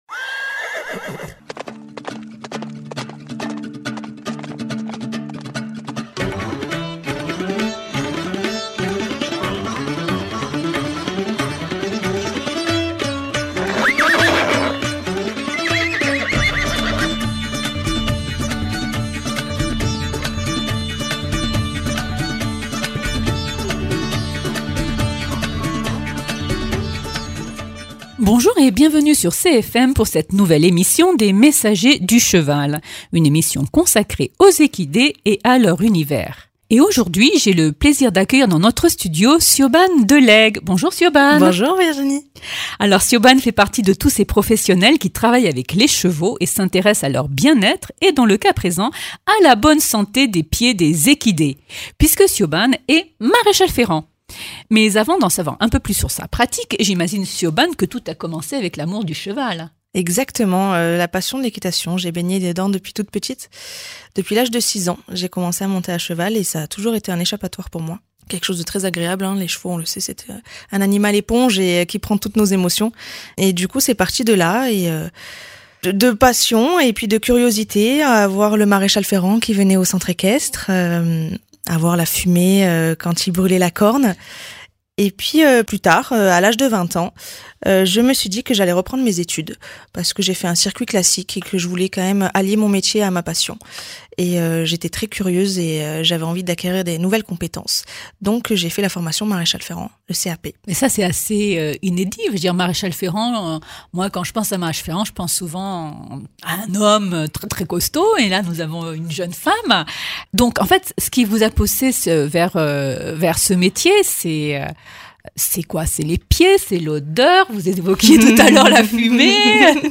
Émissions